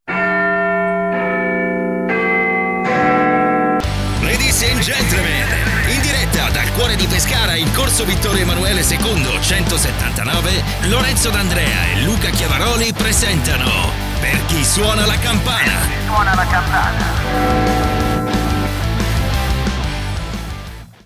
Male
Assured, Authoritative, Character, Corporate, Friendly, Warm, Versatile
Baritone vocal modulation, moderate or "extreme" characterization, at the request of the client, expressions and use of "dialectisms" or accents of various regions and nationalities, make sure that my voice is suitable in any context.
Microphone: RODE NT1 5th Gen - Neumann TLM103